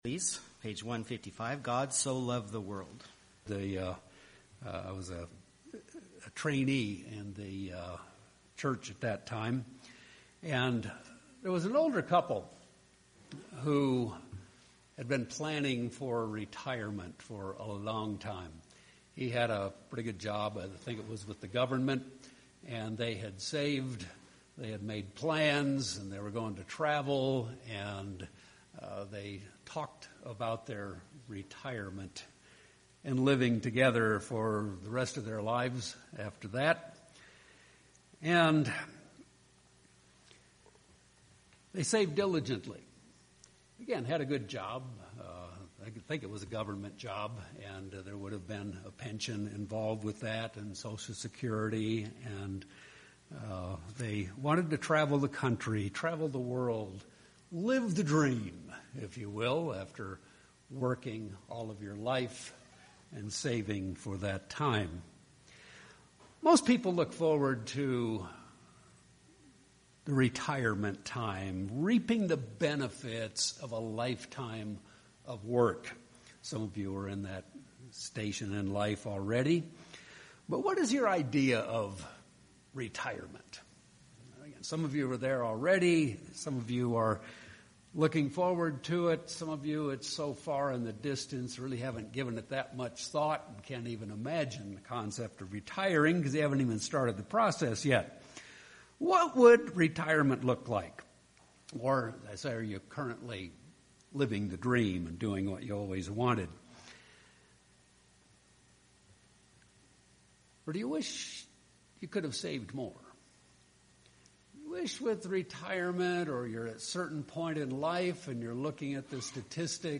Sermons
Given in Albuquerque, NM